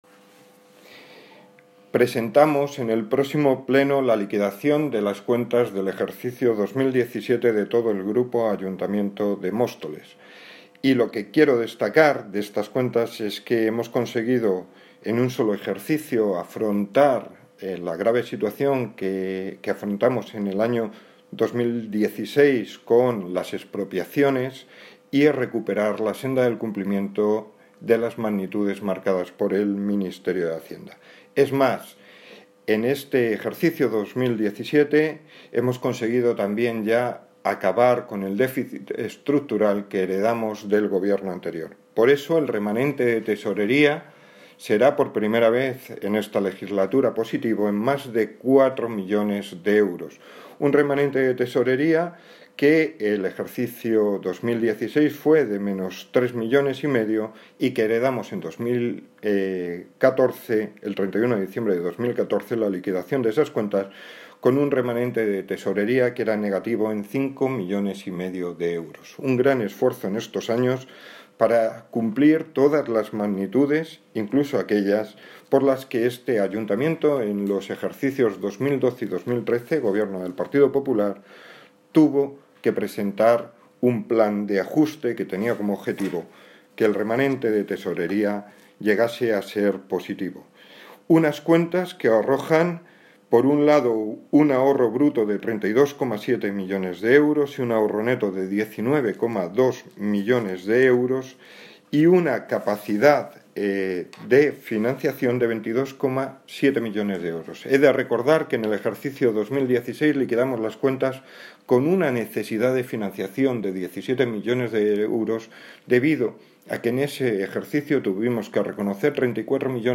Audio - Francisco Javier Gómez (Concejal de Hacienda, Transporte y Movilidad) Sobre cuentas de 2017 con remanente de tesorería